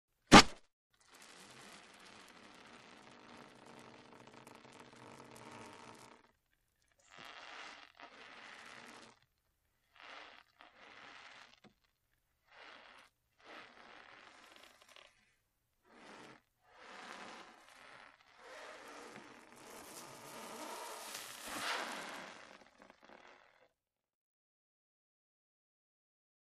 Rubber Friction And Stretching Out